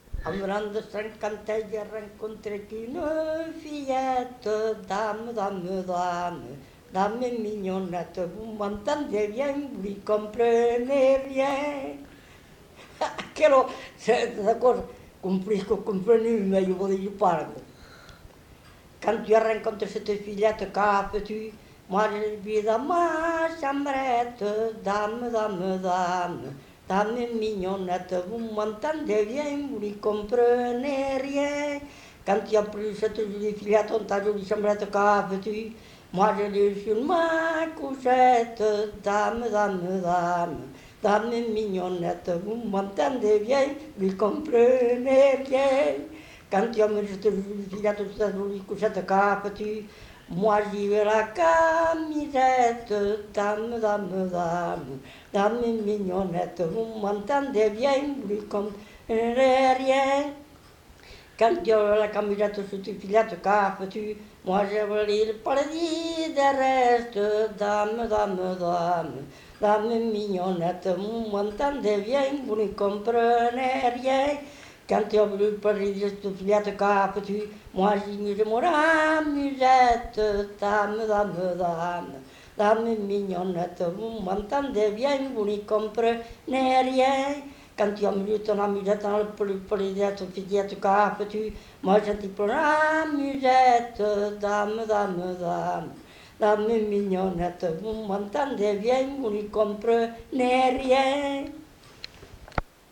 Genre : chant
Effectif : 1
Type de voix : voix d'homme
Production du son : chanté